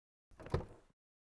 car_door_open_001.wav